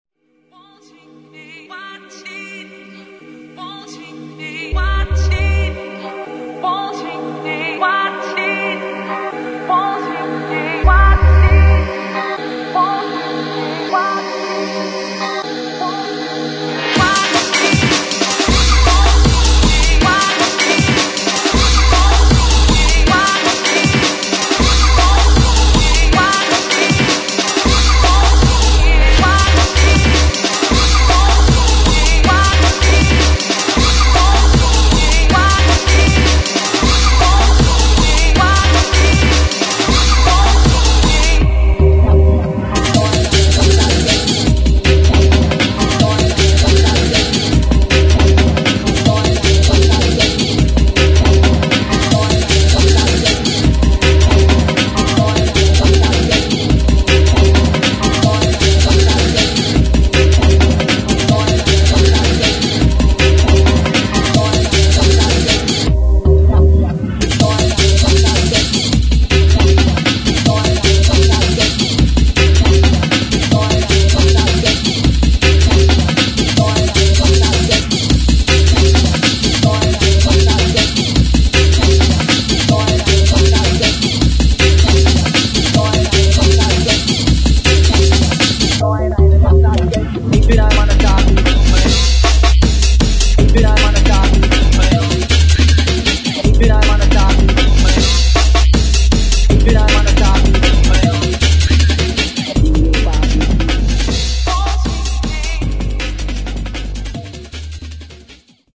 [ DRUM'N'BASS / JUNGLE ] 再プレス！